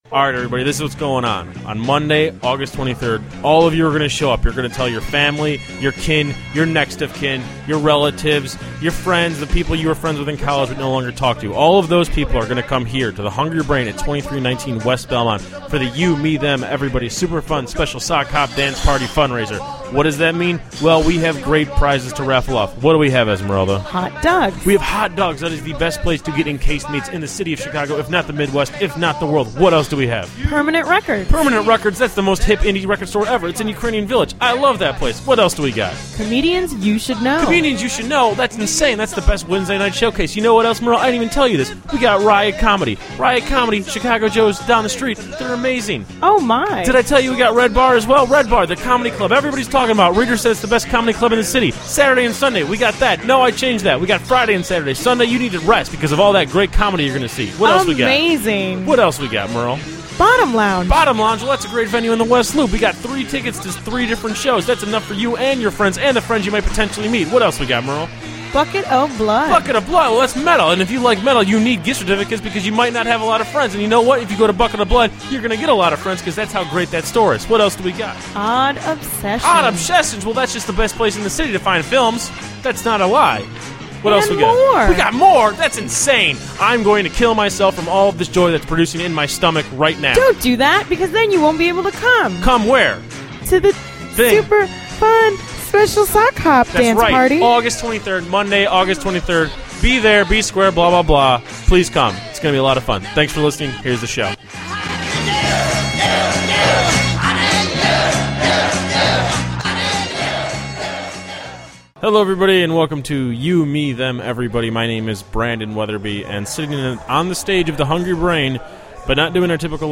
It's either charming or a bad idea that we recorded this on stage after an event.